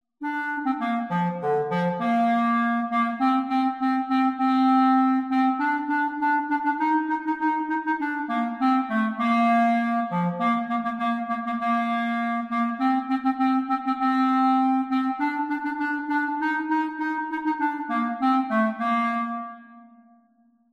Clarinet Solo